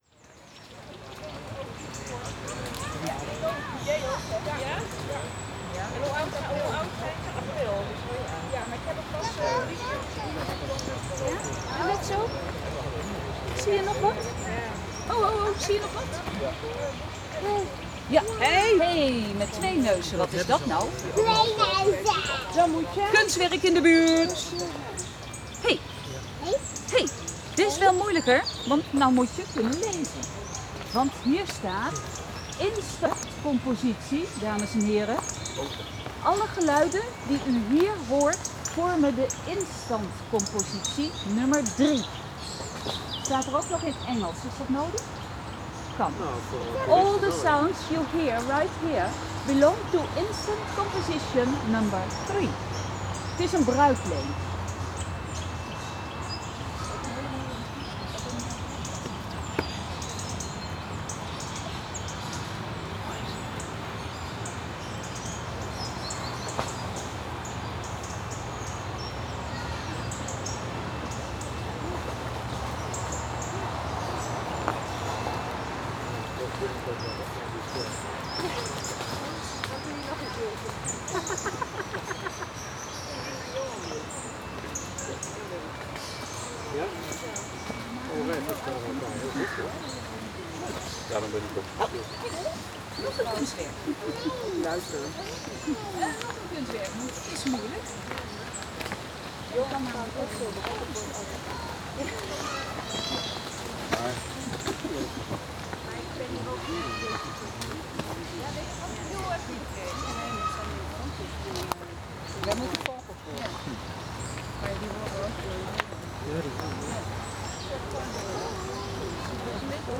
all the sounds you hear right here belong to instant composition #003